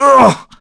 Crow-Vox_Damage_kr_02.wav